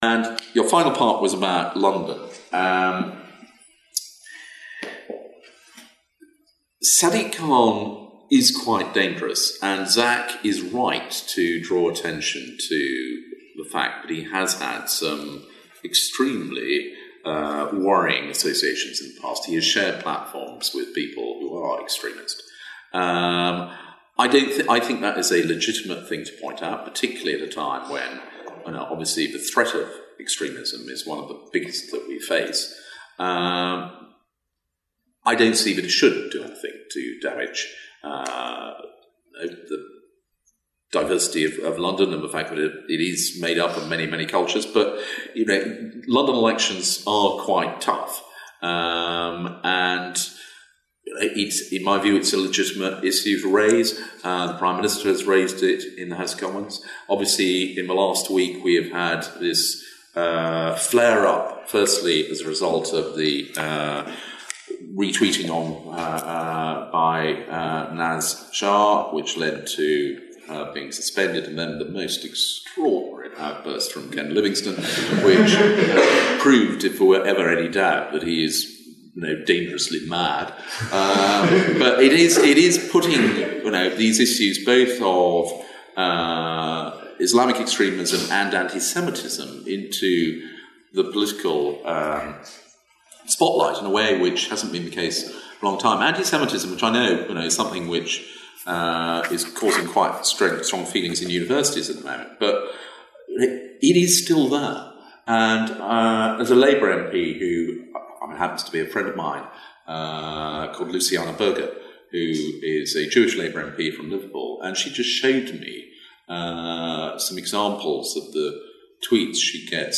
Culture secretary John Whittingdale says Labour's London mayoral candidate Sadiq Khan is “quite dangerous”, insisting the claim is legitimate given the current “threat of extremism”.